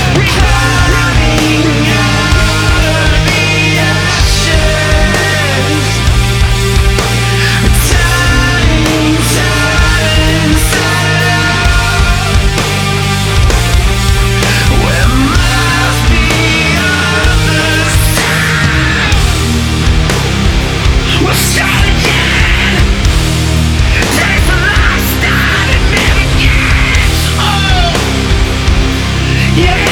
• Hard Rock